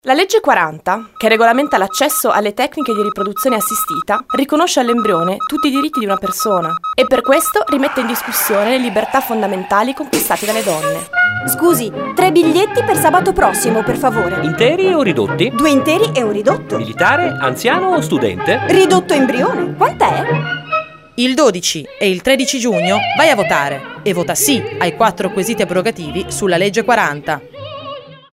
SPOT 2 | teatro